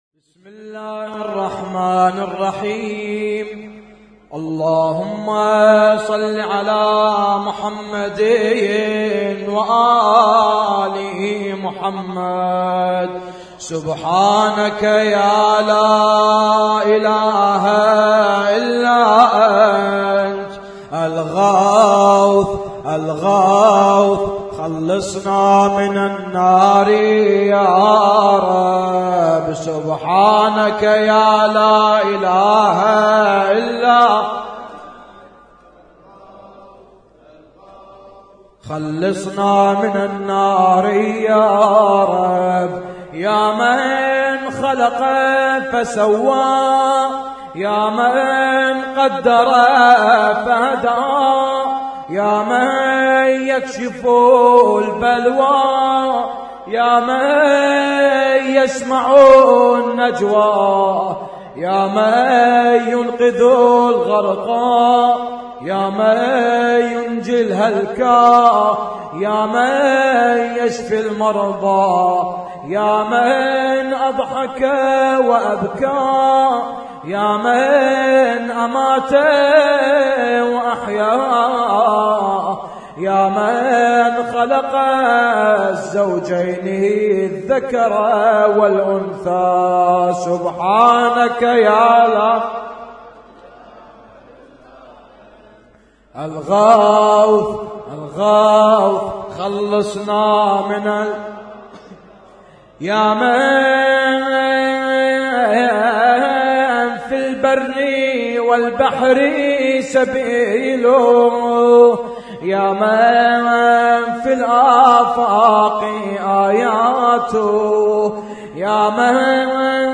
فقرات من دعاء الجوشن الكبير - احياء ليلة 21 رمضان 1436
اسم التصنيف: المـكتبة الصــوتيه >> الادعية >> ادعية ليالي القدر